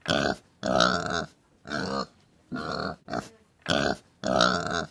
Babi_Suara.ogg